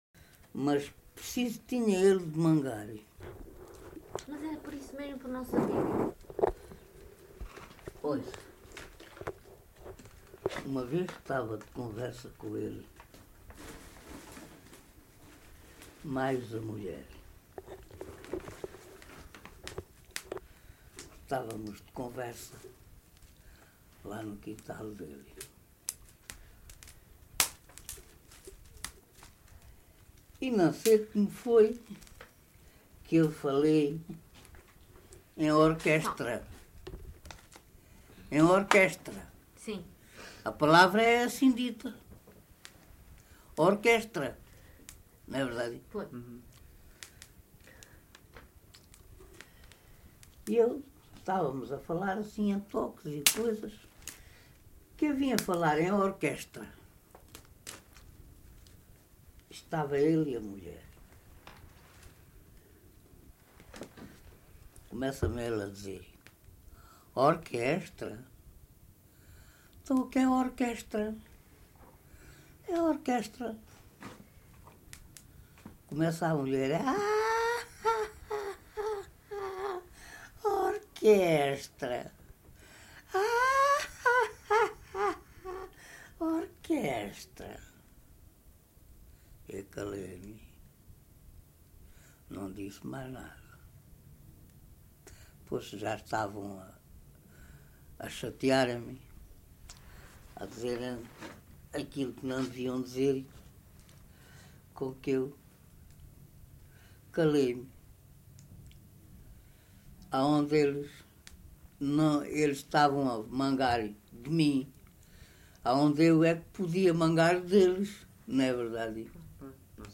LocalidadeCarrapatelo (Reguengos de Monsaraz, Évora)